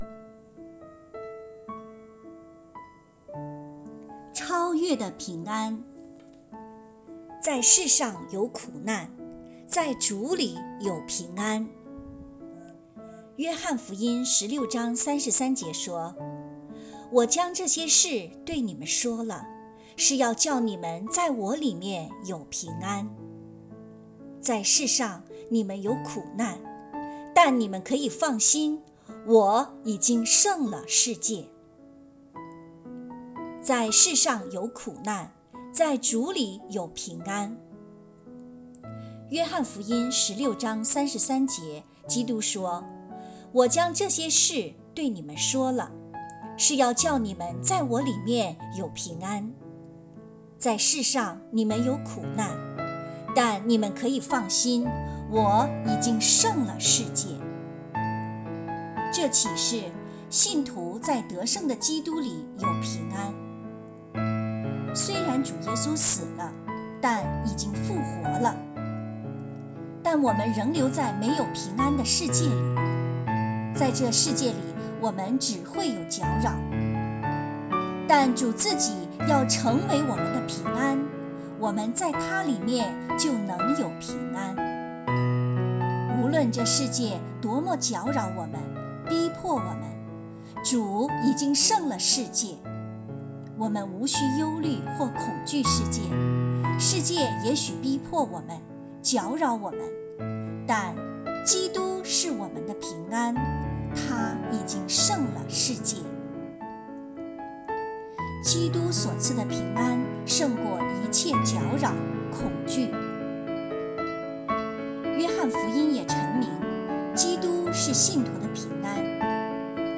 有声版